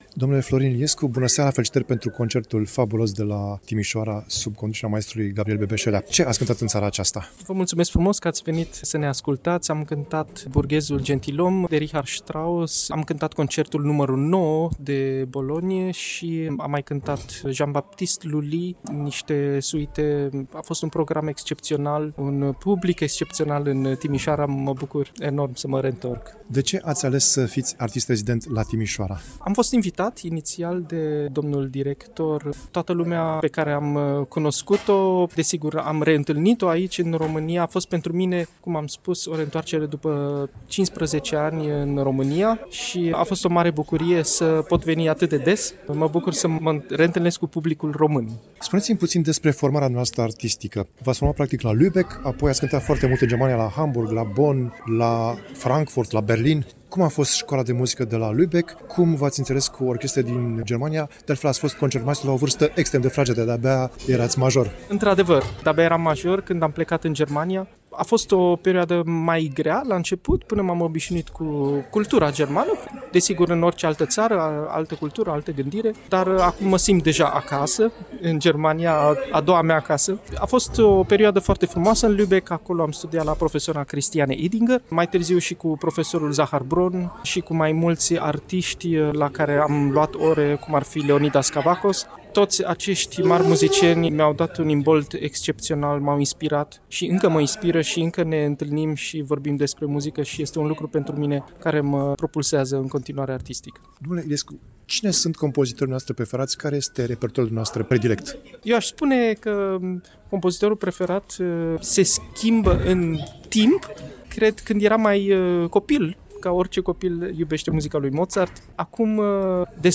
Interviu
Am discutat cu artistul după unul dintre concertele sale.